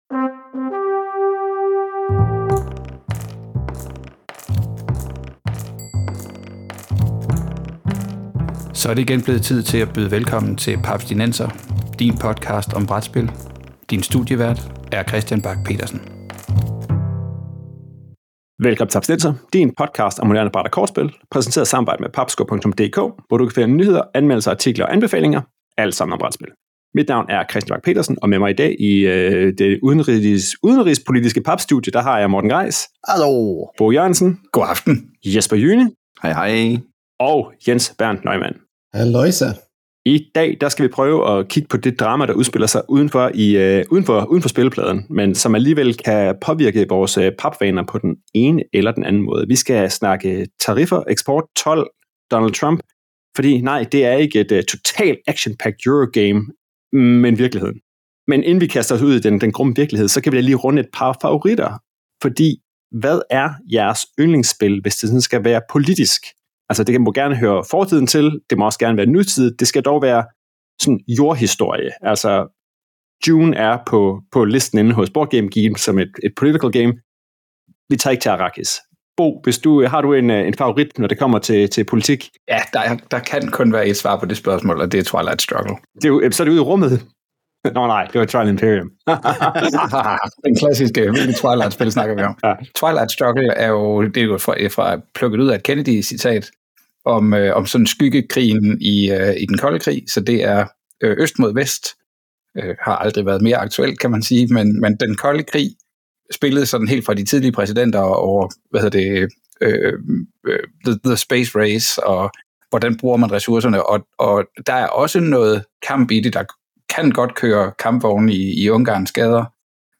Ingen af podcastværterne er økonomer eller analytikere, men lyt alligevel med, når vi dykker ned i Trump, tariffer og også lige får vendt favoritterne, når det kommer til politiske spil.